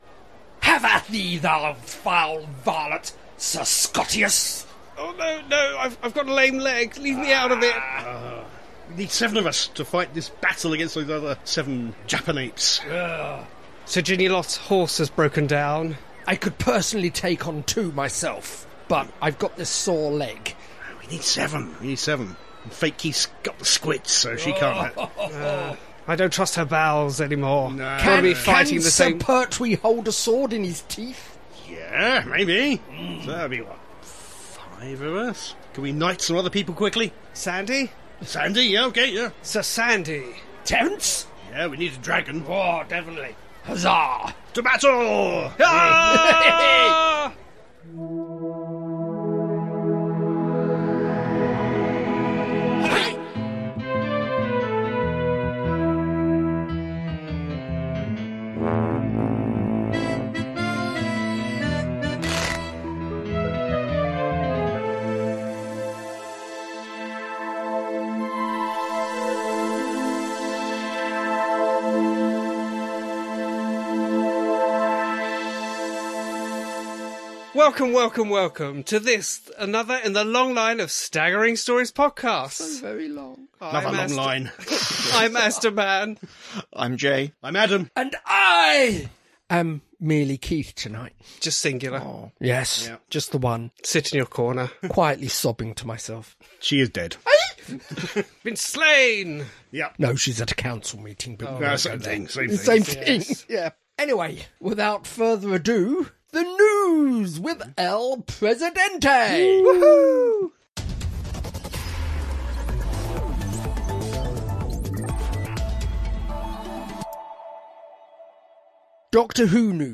00:00 – Intro and theme tune.
68:59 — End theme, disclaimer, copyright, etc.